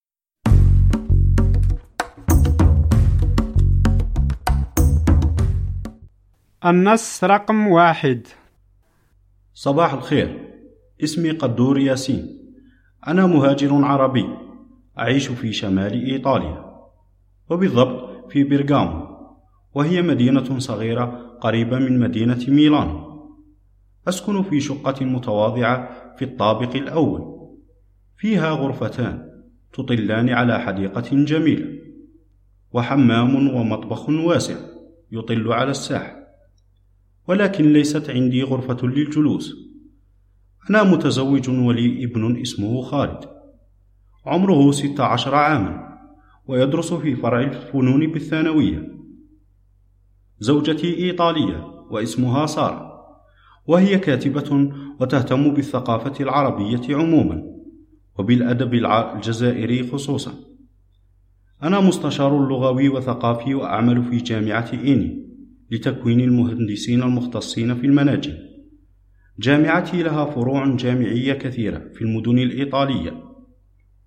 فهم المسموع